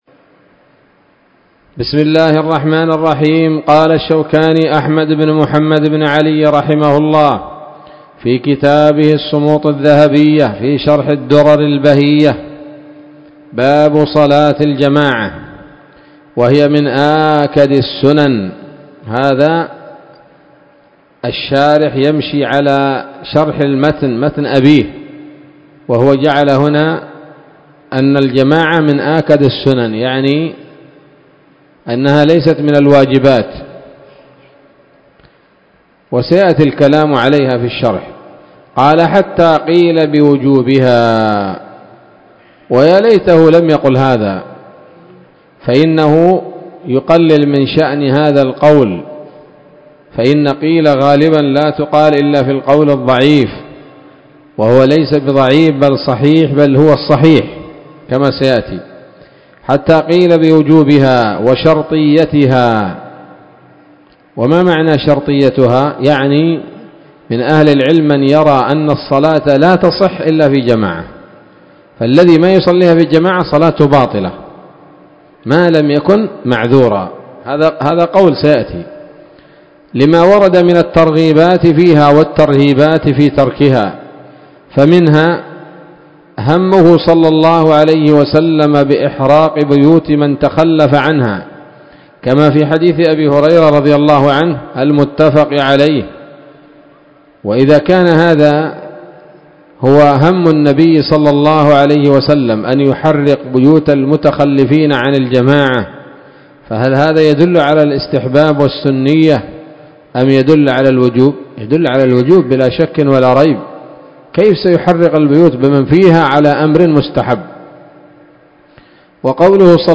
الدرس الرابع والعشرون من كتاب الصلاة من السموط الذهبية الحاوية للدرر البهية